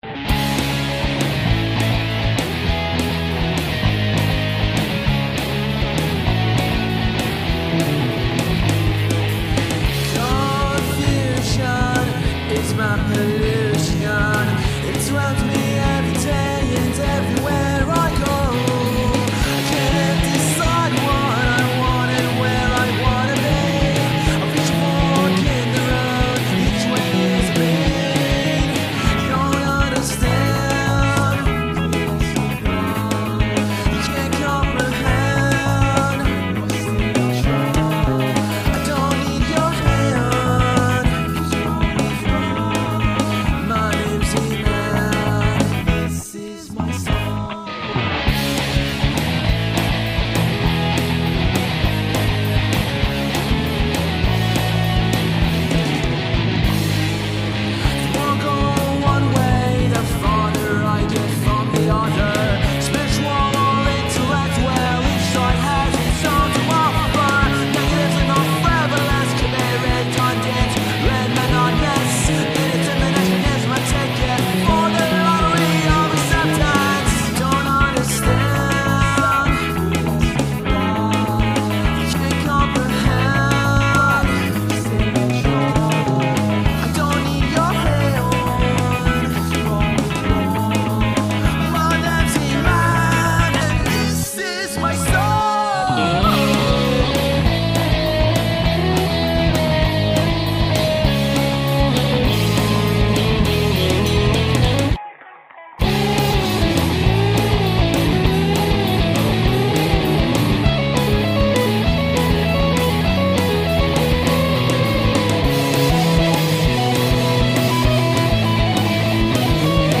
Original Music